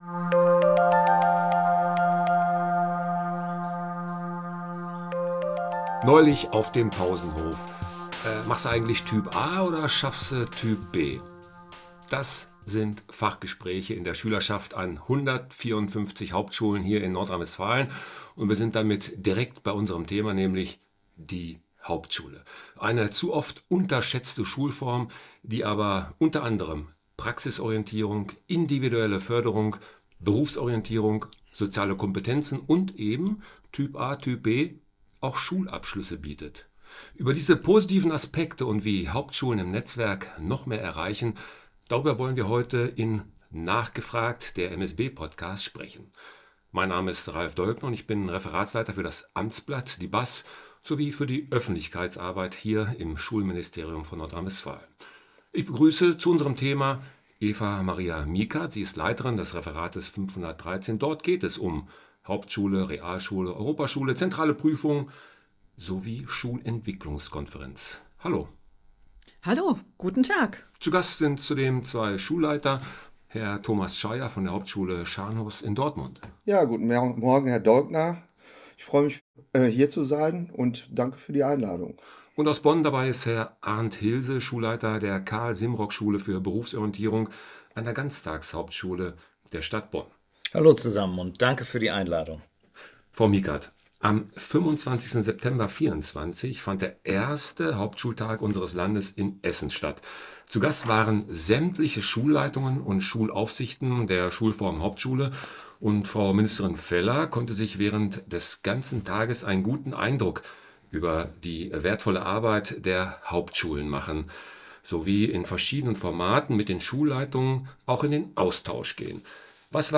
Die Hauptschulen in NRW bieten Praxisnähe, individuelle Förderung, Berufsorientierung und alle Schulabschlüsse der Sekundarstufe I. Darüber und wie die Schulen im Netzwerk noch mehr erreichen können, sprechen zwei Schulleiter und eine Referatsleiterin aus dem Ministerium.
Aufnahme vom 13. Januar 2025 im Ministerium für Schule und Bildung des Landes Nordrhein-Westfalen